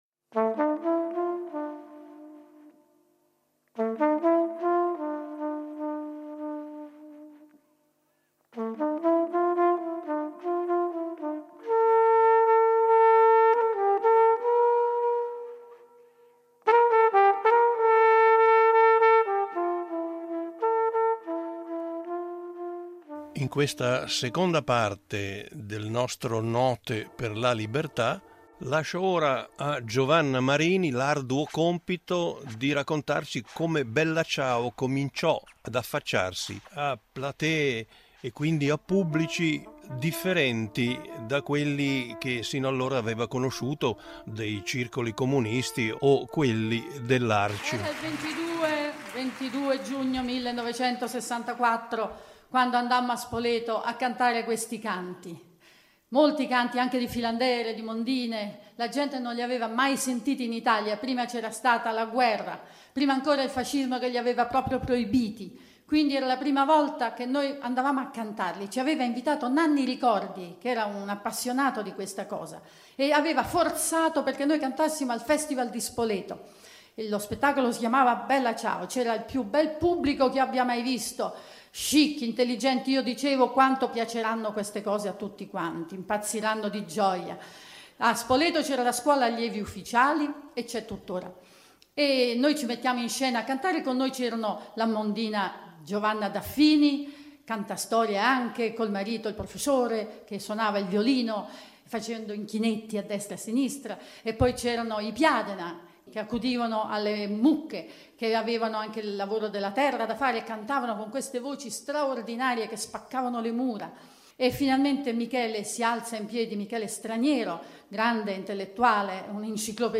A ridosso della Festa della Liberazione, Rete Due intraprende un viaggio radiofonico scandito in due puntate, attraverso le musiche della Resistenza antifascista italiana.